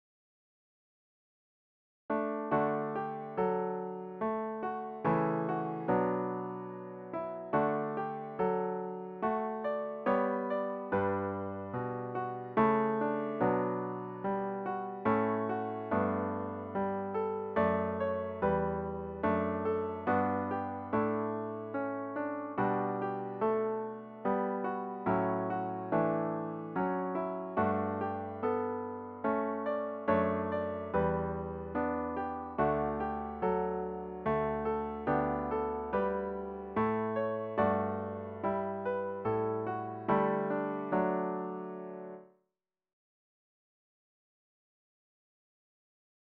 The hymn should be performed at a calmly lilting♩= ca. 72.